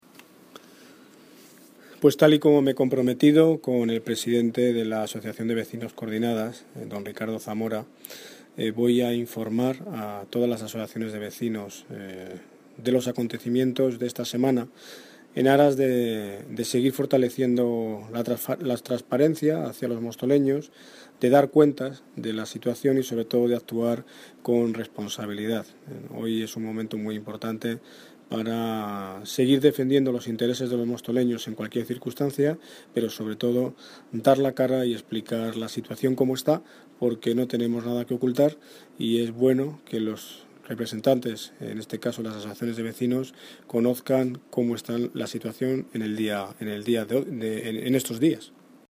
Audio - Daniel Ortiz (alcalde de Móstoles) Ortiz Reunión Vecinos